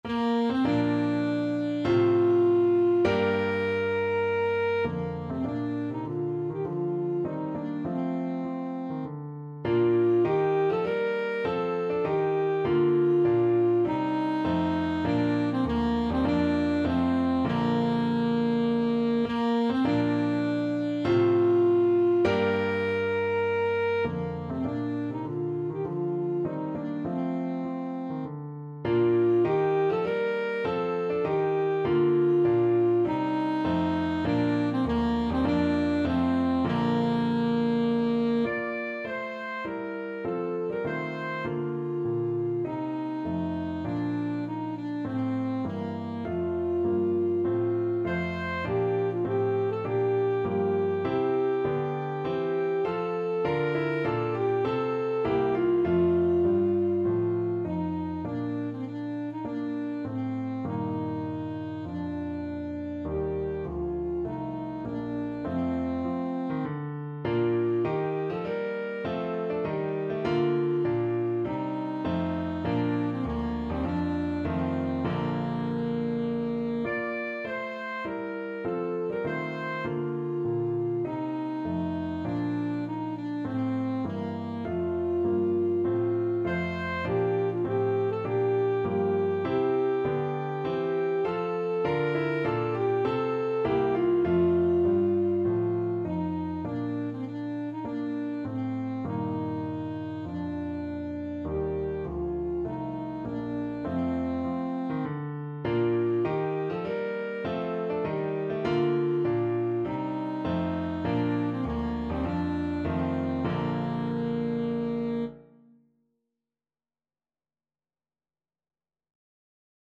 Alto Saxophone
4/4 (View more 4/4 Music)
Bb major (Sounding Pitch) G major (Alto Saxophone in Eb) (View more Bb major Music for Saxophone )
Bb4-D6
Classical (View more Classical Saxophone Music)
handel_scipio_march_ASAX.mp3